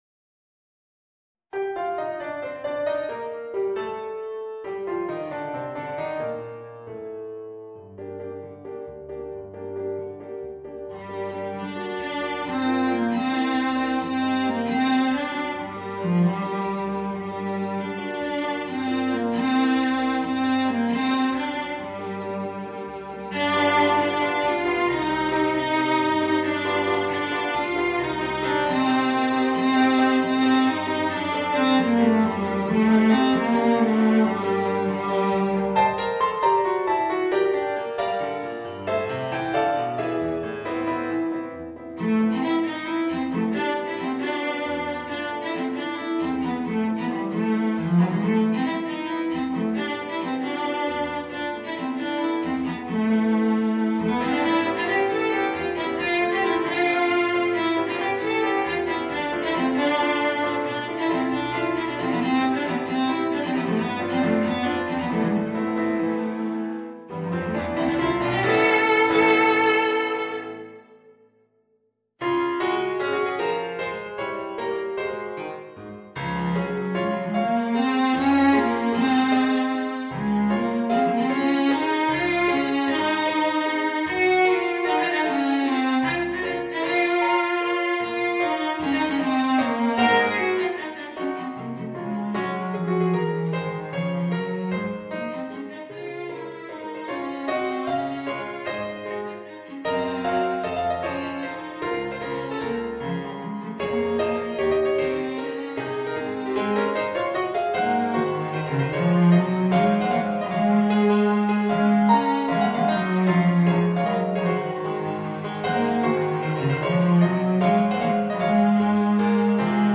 Violoncello w/ Audio